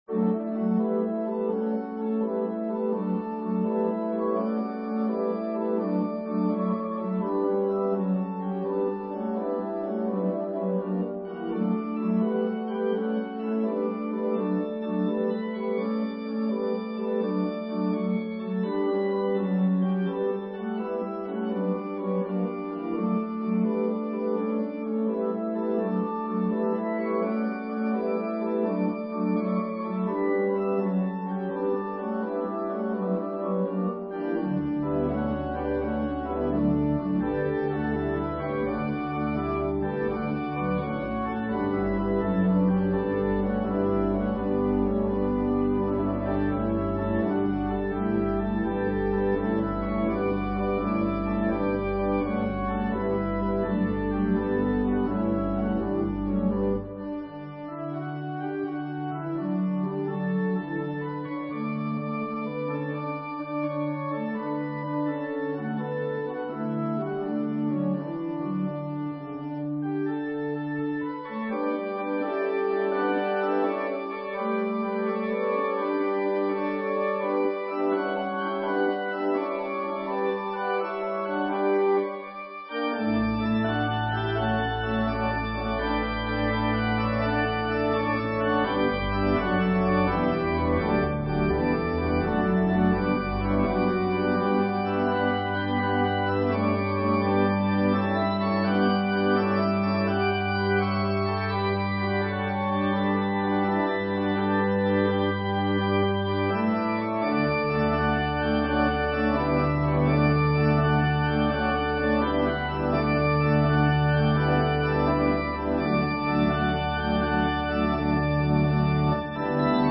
An organ solo version of Mack Wilberg's choral arrangement of this joyous Easter song.